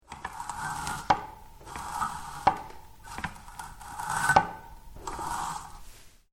Flachsverarbeitung-Hecheln-kurz.mp3